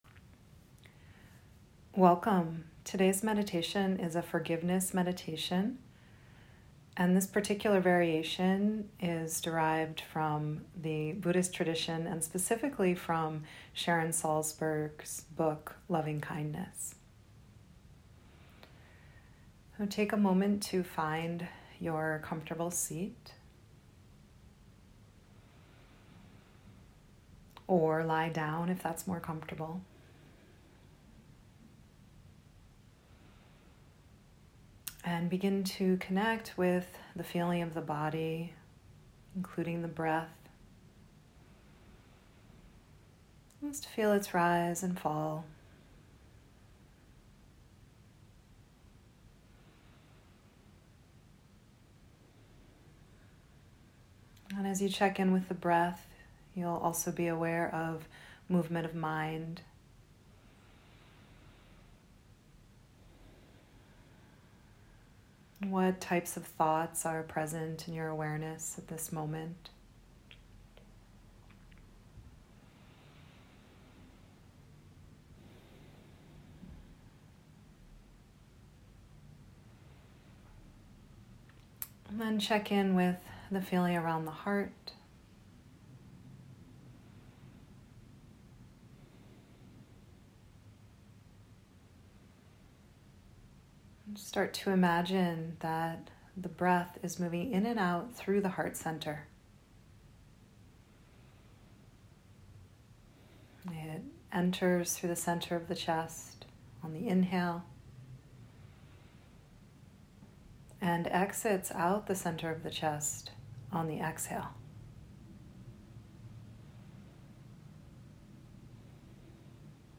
Forgiveness practice is an essential component of healing past wounds and freeing ourselves from the cycle of shame and blame. This audio meditation is inspired by Sharon Salzberg's instructions in her book Lovingkindess.